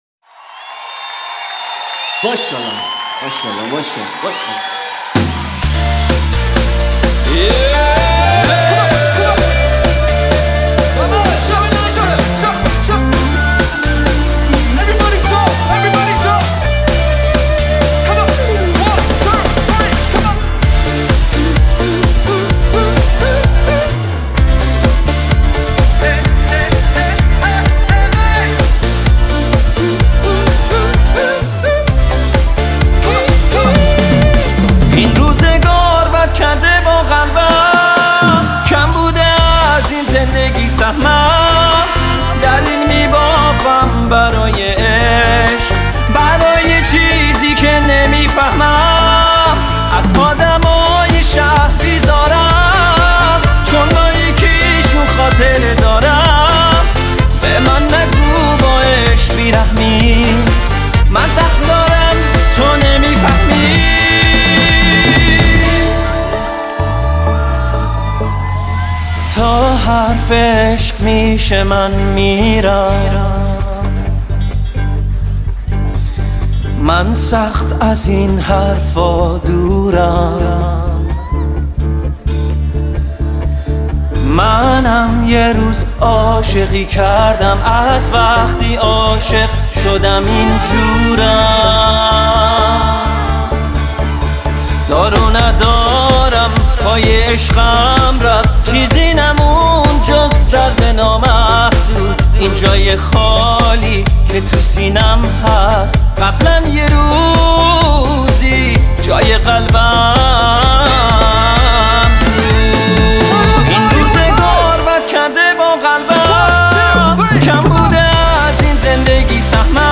دانلود Live (زنده)